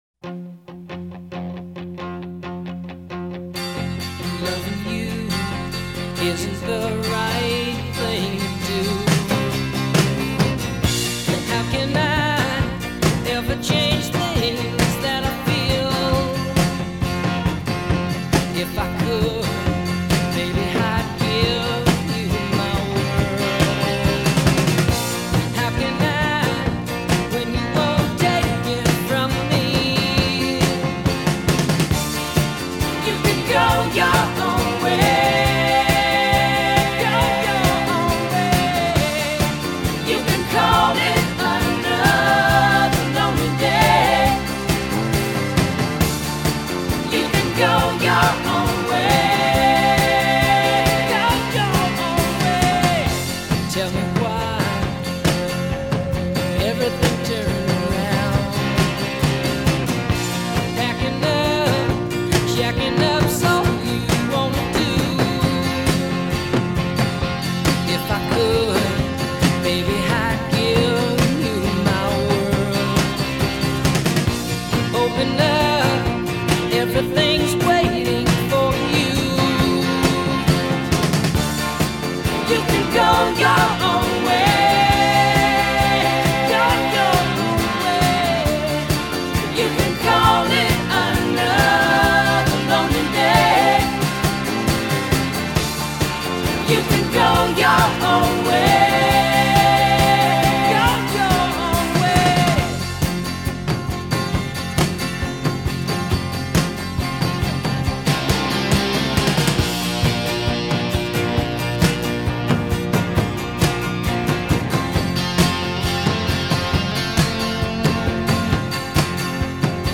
Capo 3rd Fret - 4/4 Time